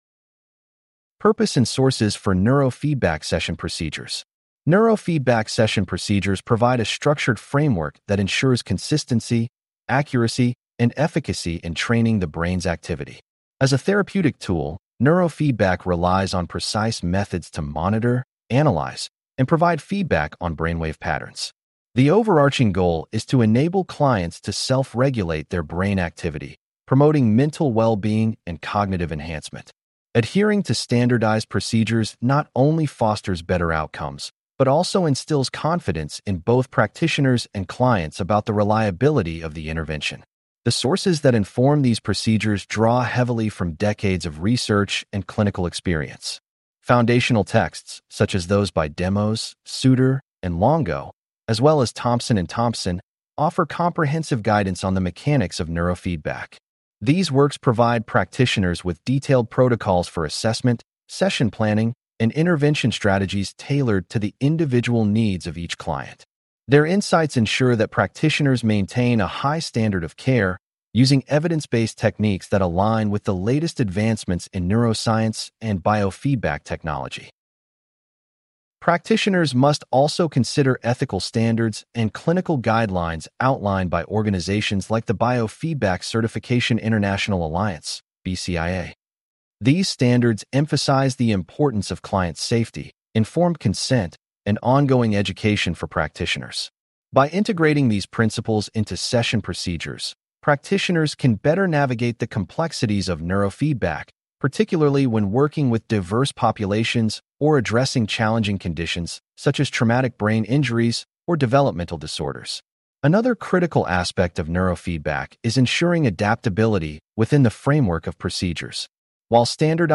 Please click on the podcast icon below to hear a full-length lecture.